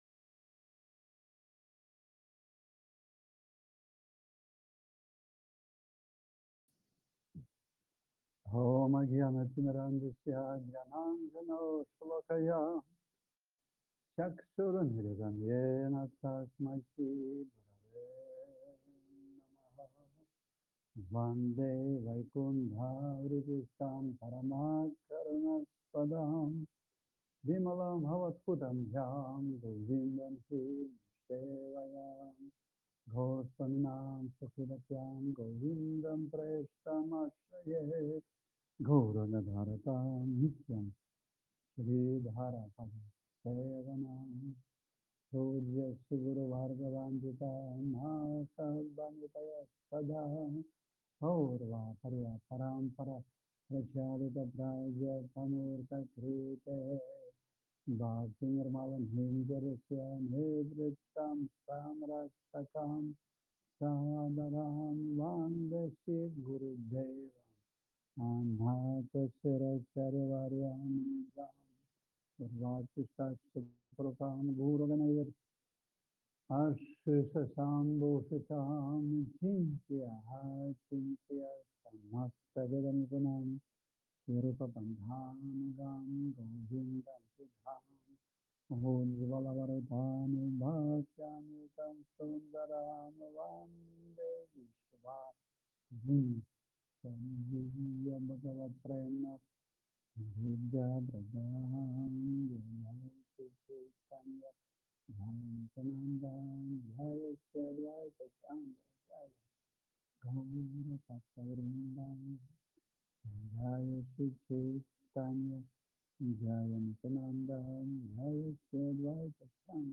Пури Дхама, Индия
Пури Дхама, Индия скачать (формат MP3, 16 МБ ) После 8:21 звук улучшается, до этого времени тихий.